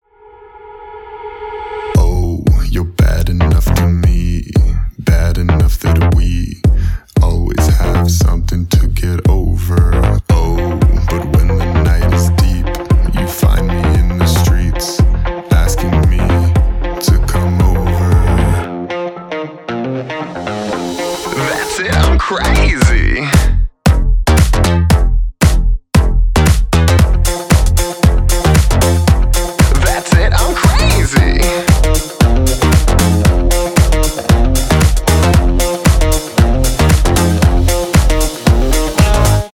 • Качество: 320, Stereo
ритмичные
dance
Electronic
EDM
house
Песня из рекламы АЙФОН 8 красный